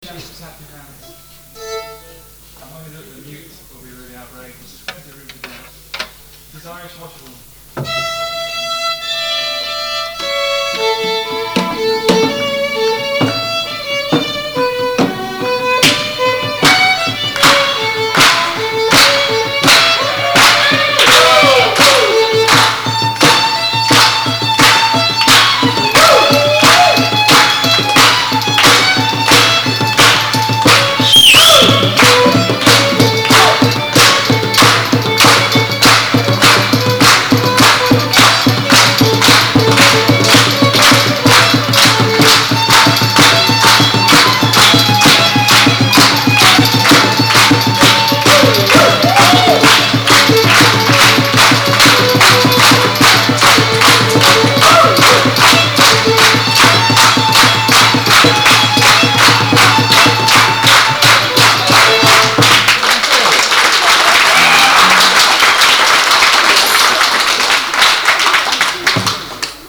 Recorded at Harvester's Folk Club, City of Leicester College of Edication, Scraptoft, Leicester in 1976 when the line up of Bodgers Mate was:-
Mandolin, Bouzouki, viola, Vocals
Pipes, Whistle, guitar, Vocals
Fiddle, Vocals